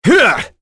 Roi-Vox_Attack4.wav